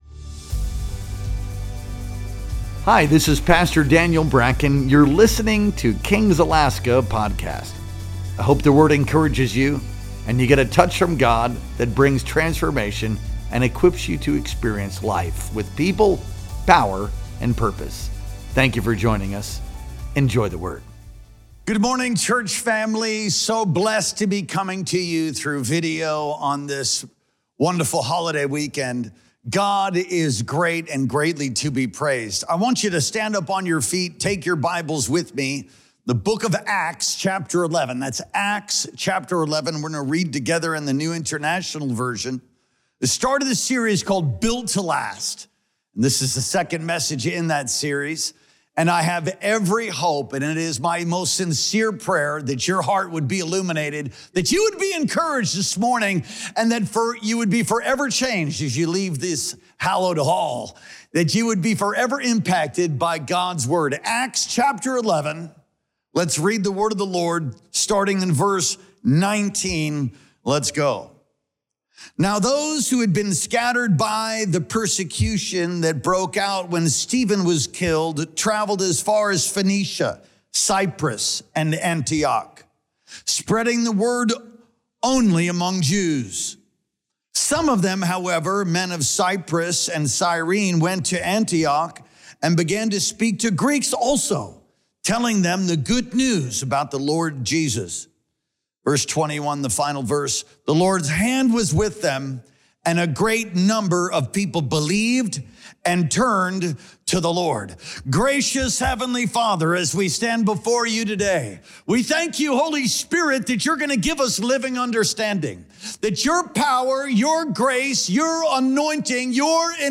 Our Sunday Morning Worship Experience streamed live on August 31st, 2025.